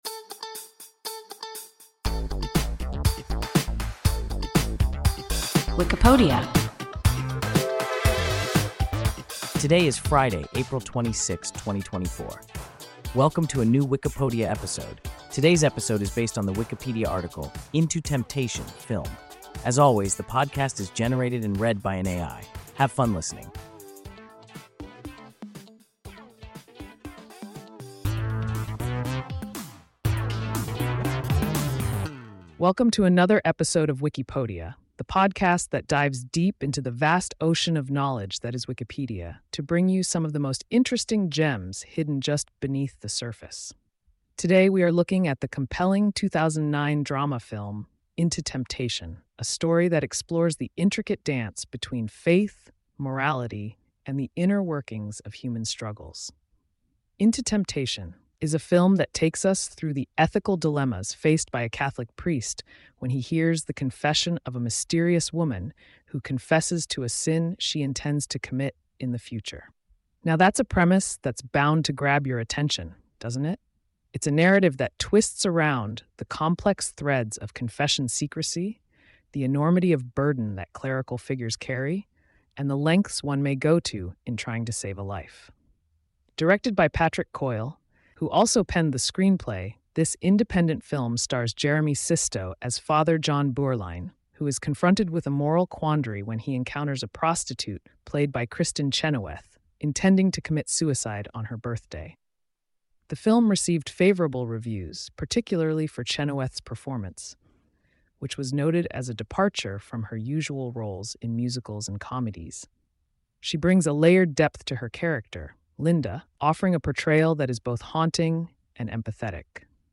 Into Temptation (film) – WIKIPODIA – ein KI Podcast
Wikipodia – an AI podcast